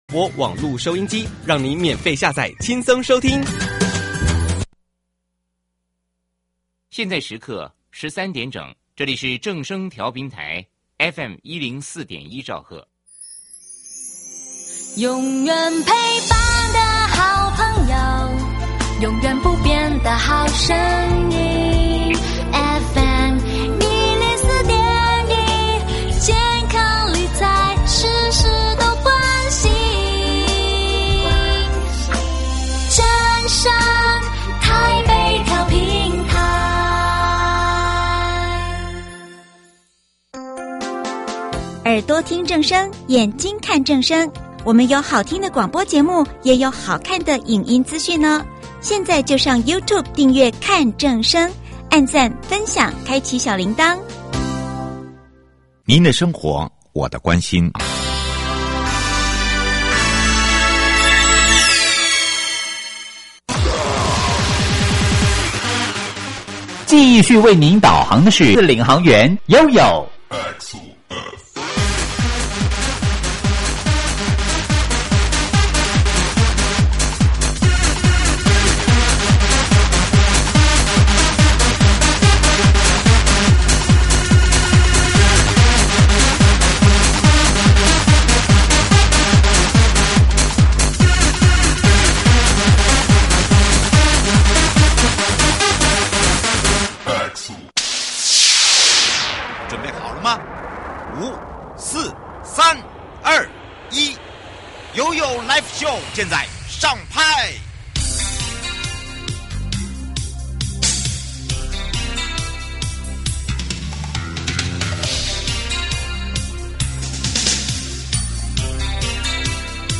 受訪者： 營建你我他 快樂平安行~七嘴八舌講清楚~樂活街道自在同行! 主題：自行車可否騎乘於騎樓、人行道？